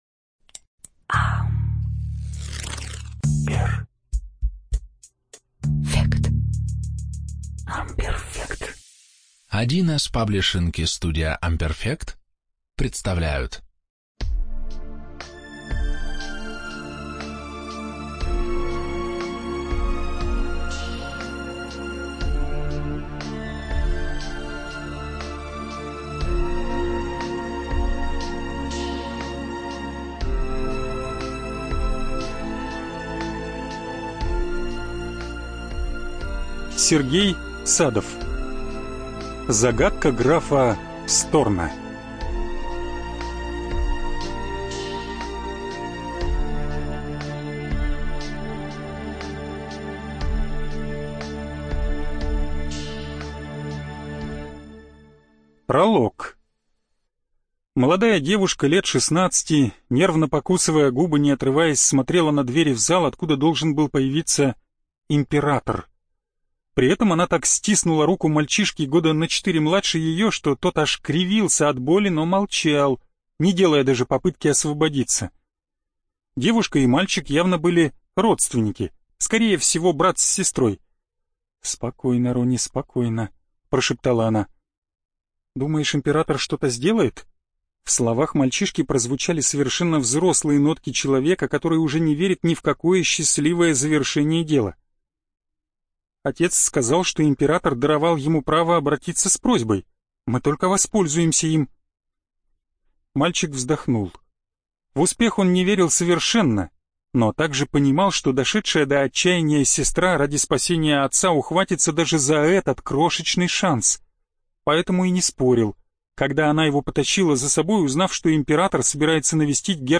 Студия звукозаписи1С-Паблишинг